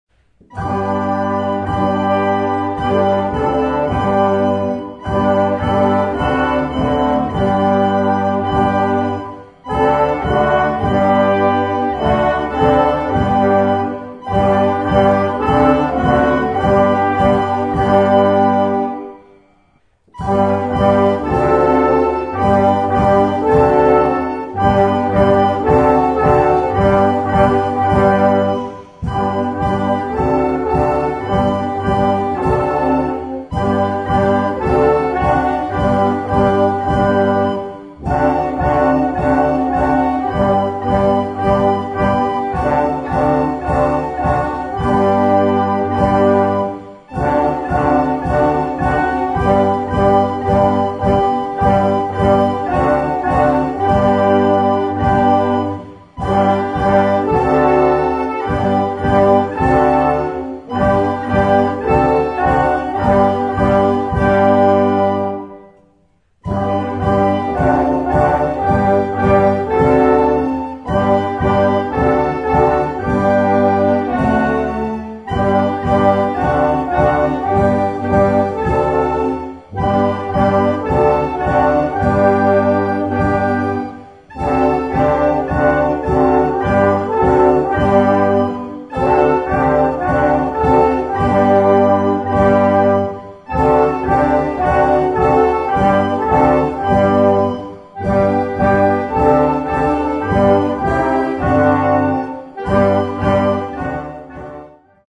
Groupe de flûtes à bec avec accompagnement orchestral
Partitions pour ensemble flexible, 4-voix + percussion.